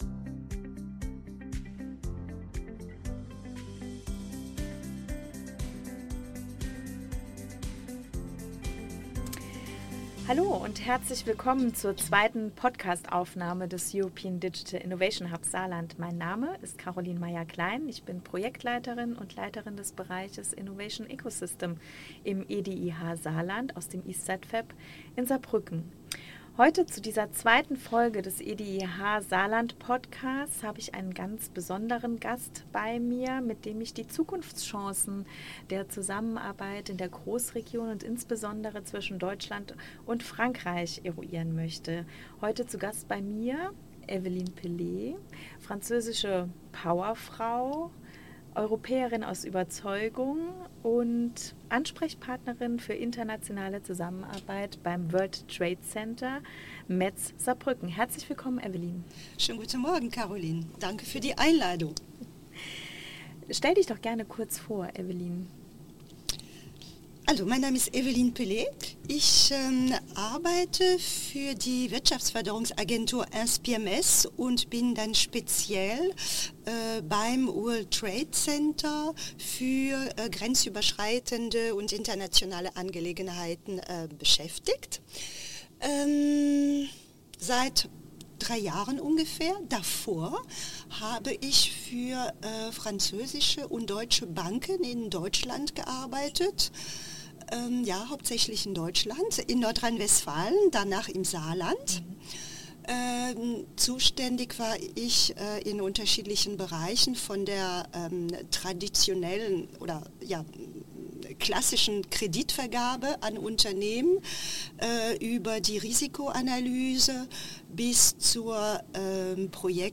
Perspektiven über die Vorteile und Herausforderungen der deutsch-französischen Kooperation werden in unserer Diskussion beleuchtet. Gemeinsam sprechen wir darüber, wie Unternehmen von internationalen Netzwerken profitieren können.